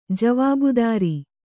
♪ javābudāri